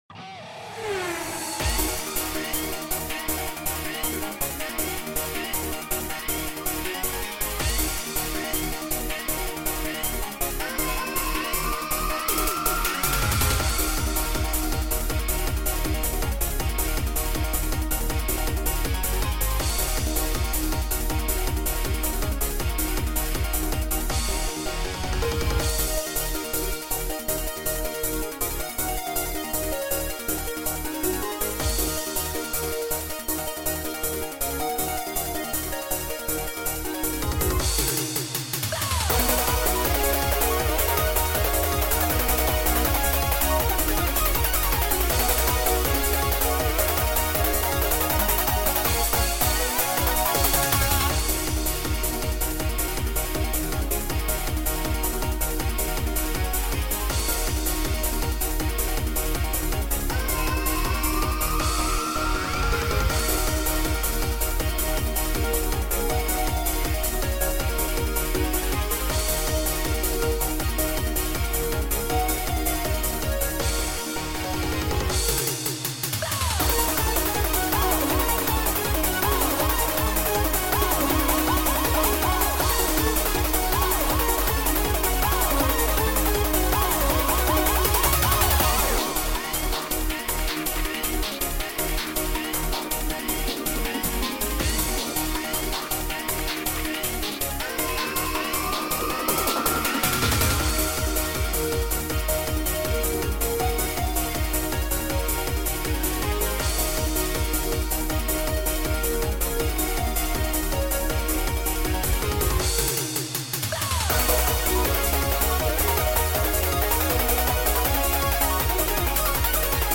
eurobeat cover
genre:eurobeat